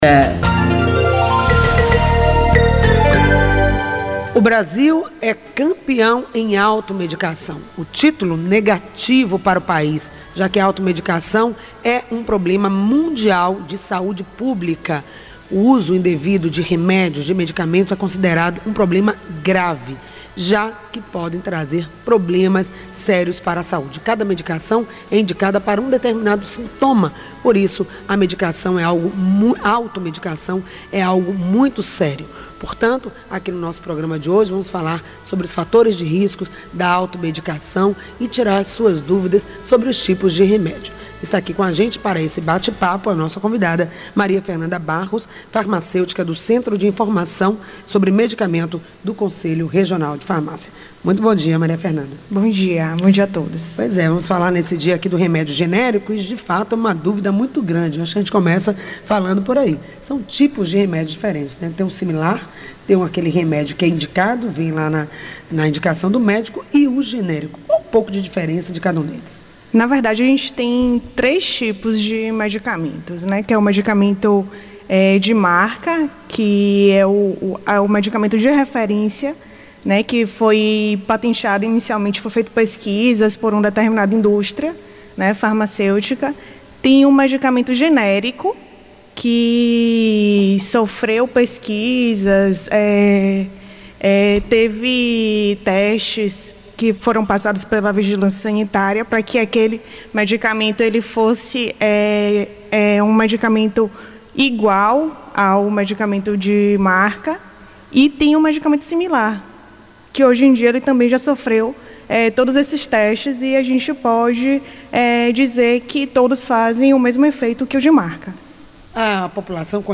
Ouça na íntegra a entrevista!